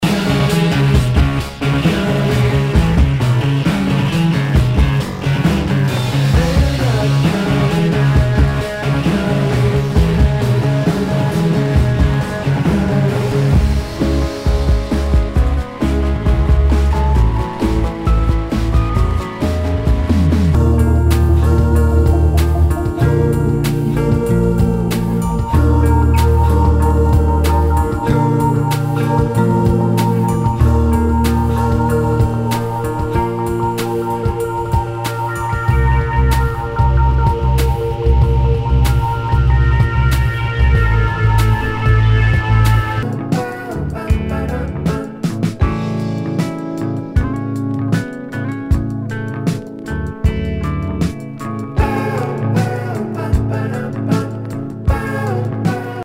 ROCK/POPS/INDIE
ナイス！インディーロック！